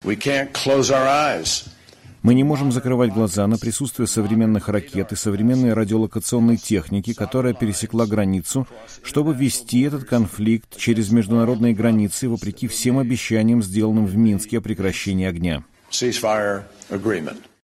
Госсекретарь Джон Керри выступает на пресс-конференции в Киеве после встречи с президентом Украины Петром Порошенко